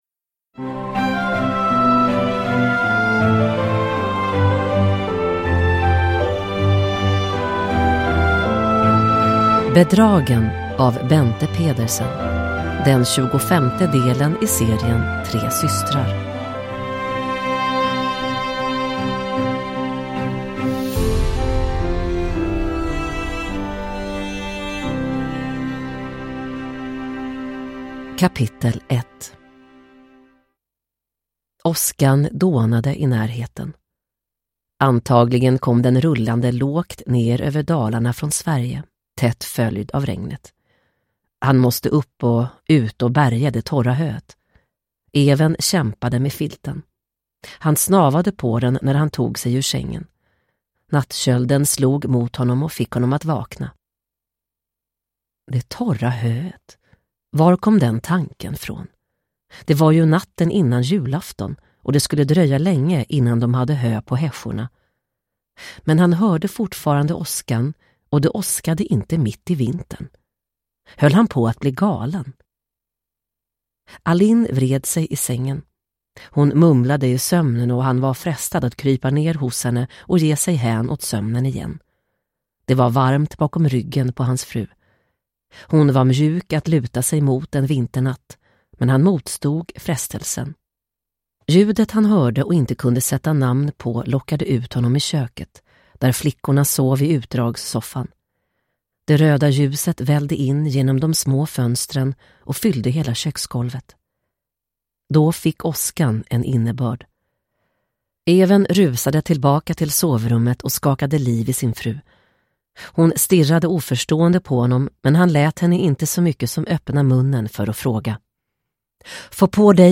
Bedragen – Ljudbok – Laddas ner